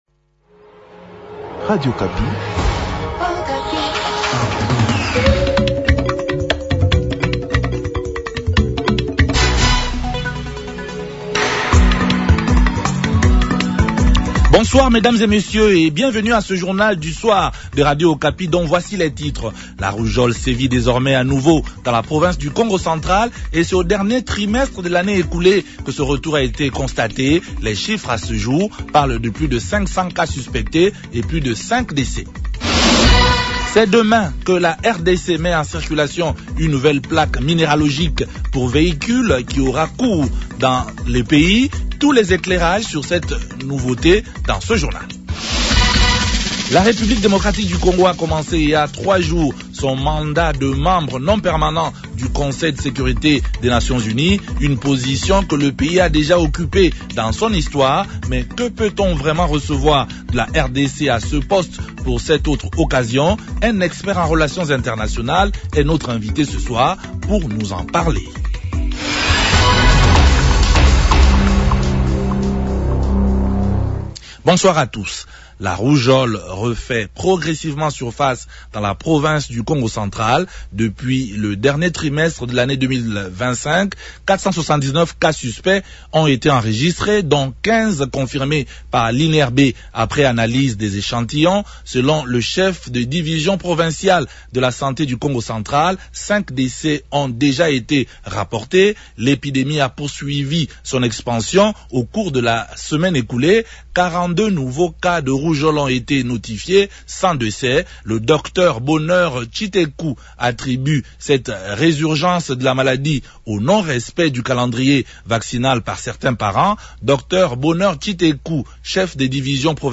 Un expert en relations internationales est notre invite ce soir pour nous éclairer.